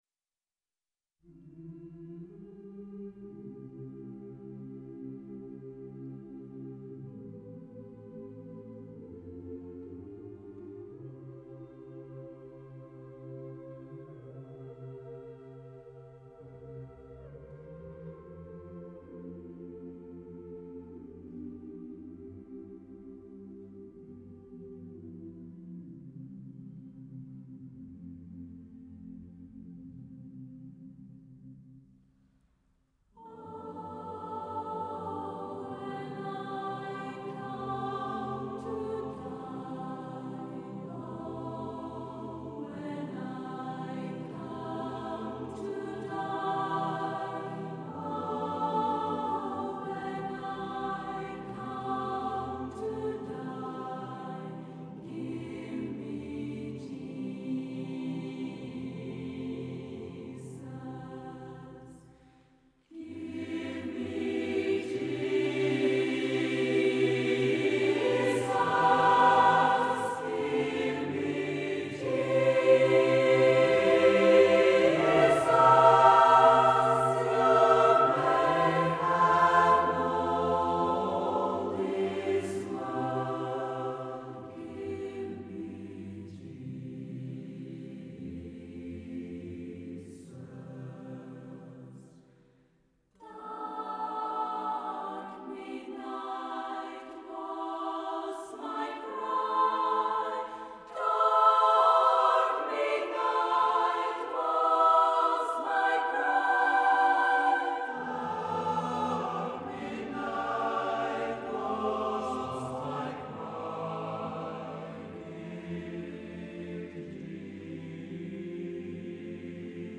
For Choir